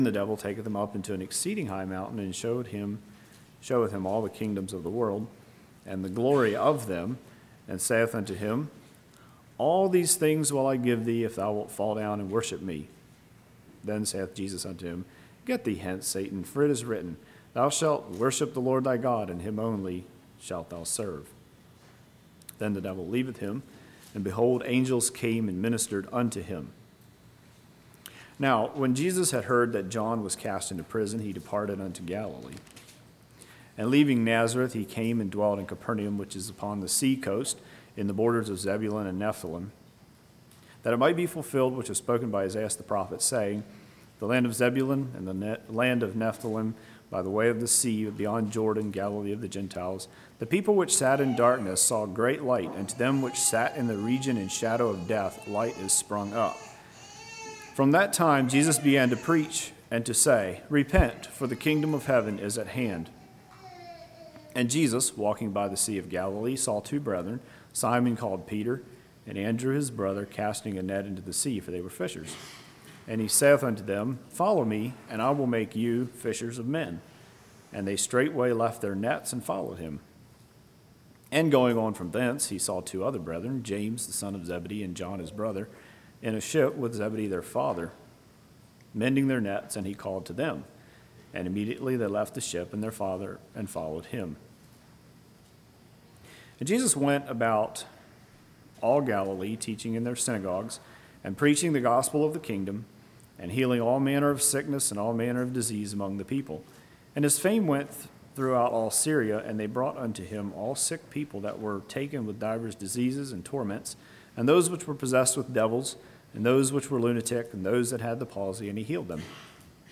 2016 Sermon ID